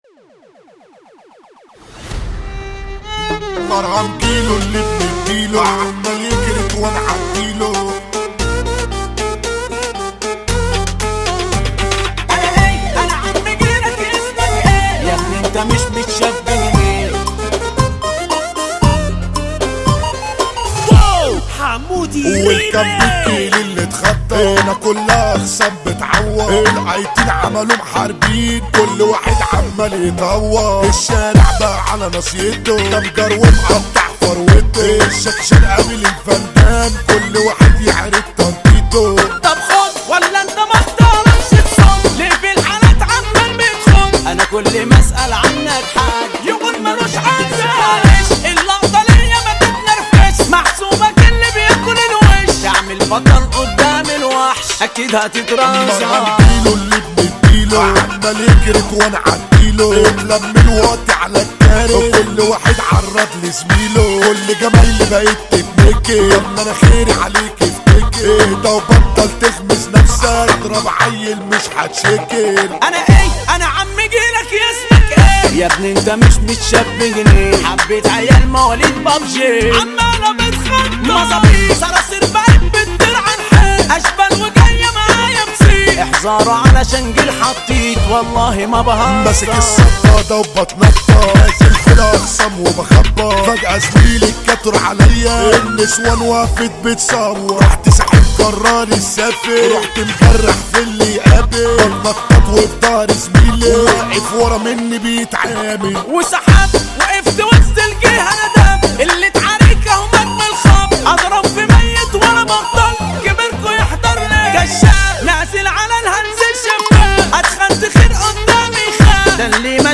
مهرجانات جديدة